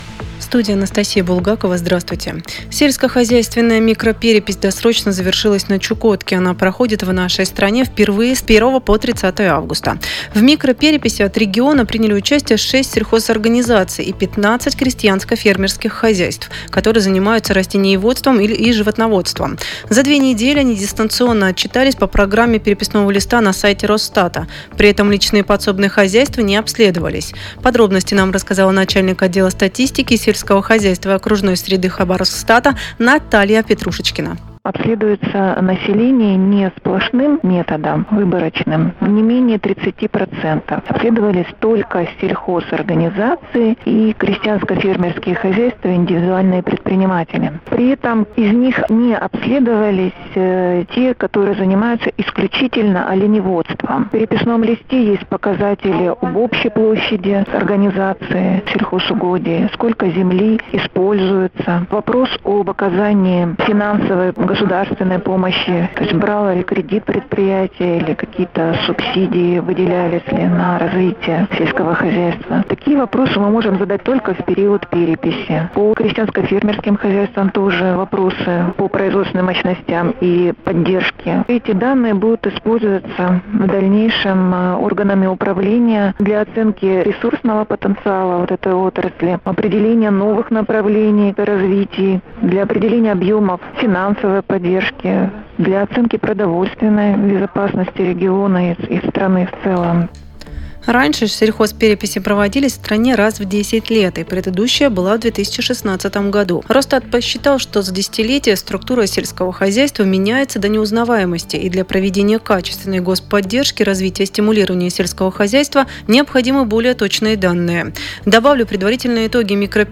Радиоэфир радио "Пурга" г. Анадырь